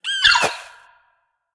Sfx_creature_babypenguin_death_land_03.ogg